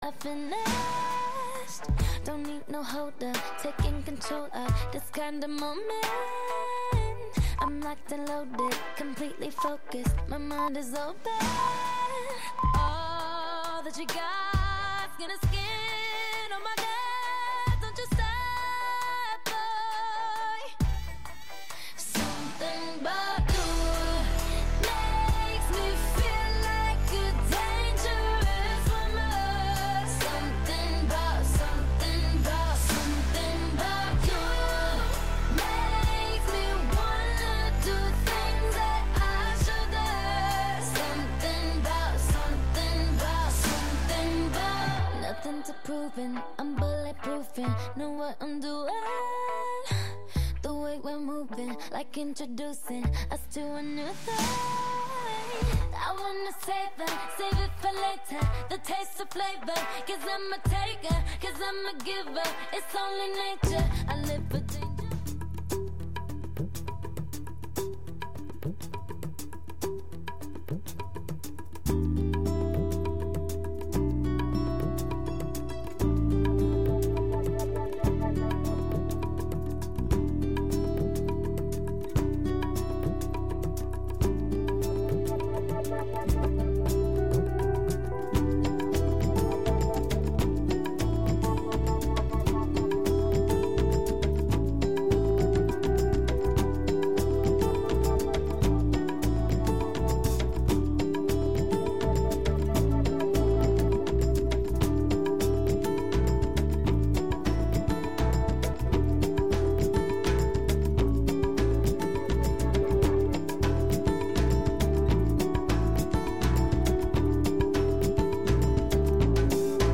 Recorreguts musicals pels racons del planeta, música amb arrels i de fusió. Sense prejudicis i amb eclecticisme.